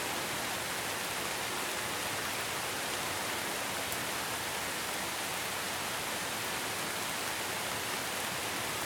rain.ogg